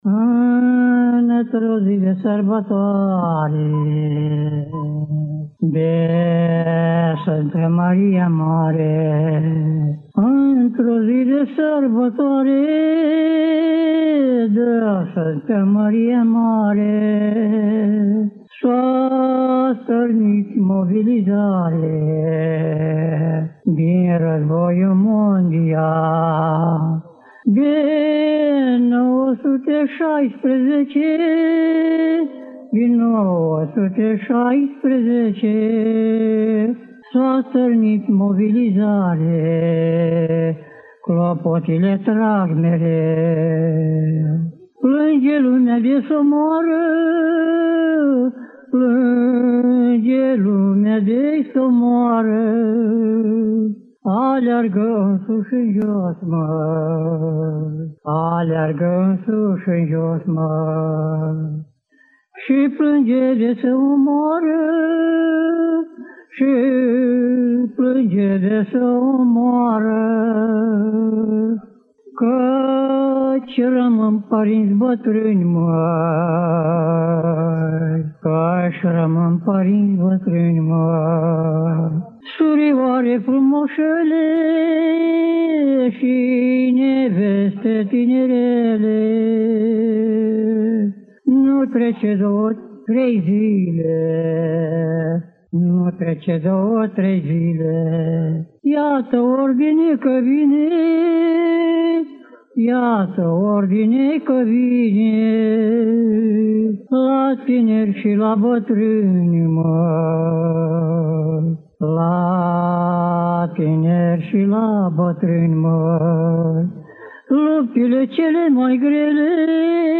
De Sântă Măria Mare – în interpretarea rapsodului popular